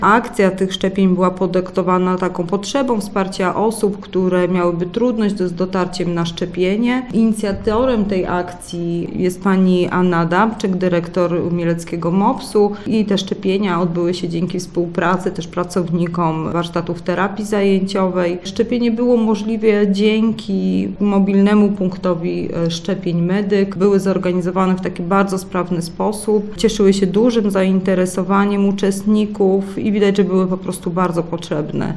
Mówi zastępca Prezydenta Mielca, Adriana Miłoś.